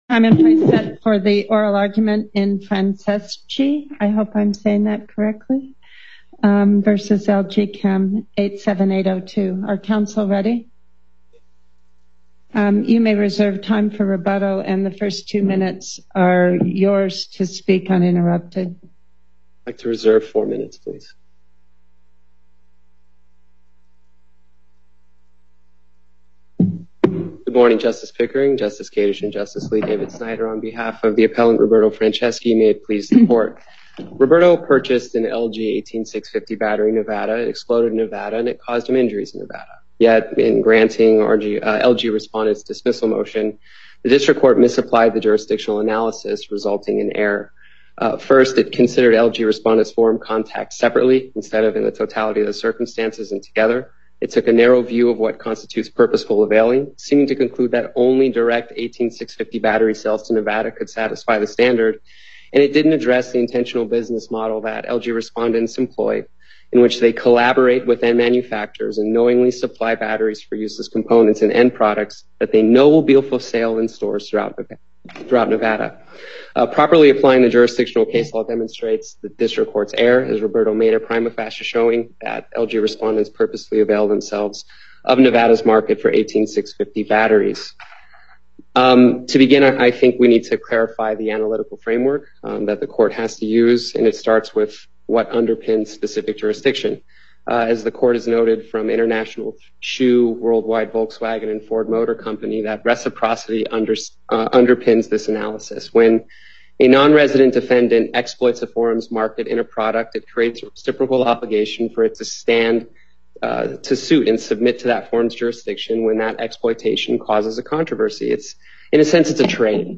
Before Panel B25 Justice Pickering Presiding Appearances